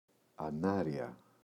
ανάρια [a’narʝa]